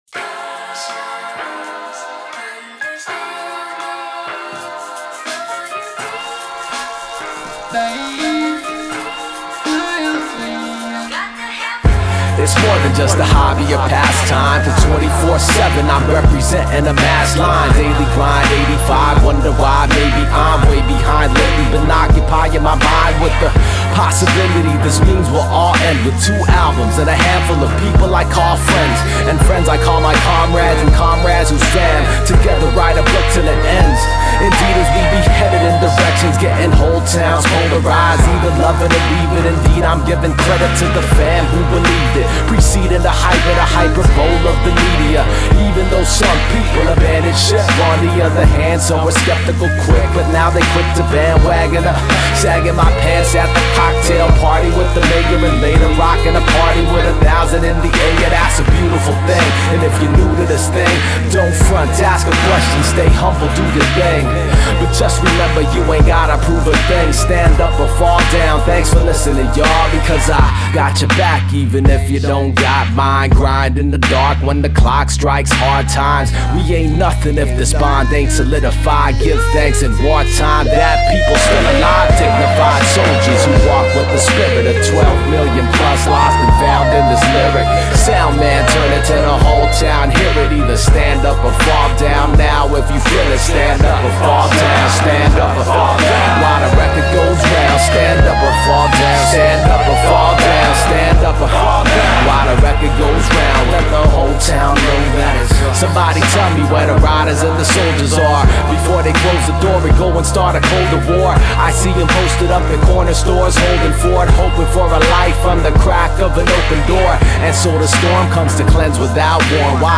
Seattle producer and emcee duo